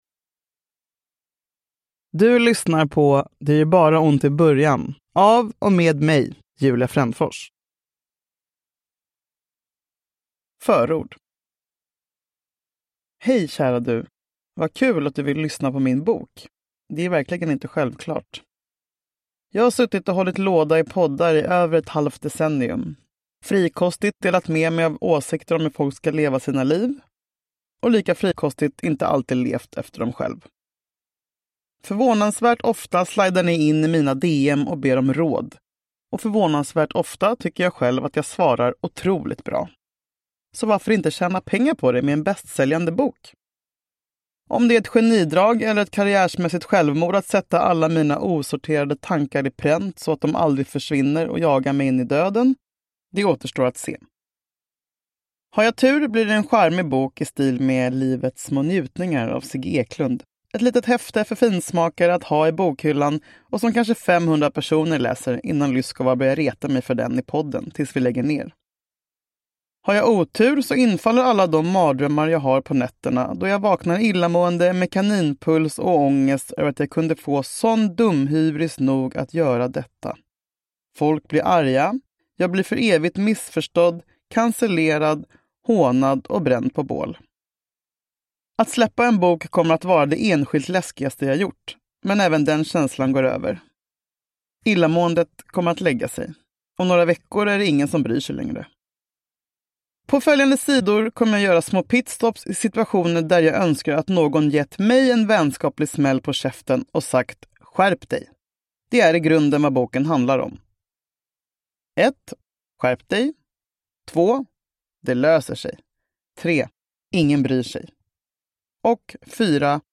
Det gör bara ont i början – Ljudbok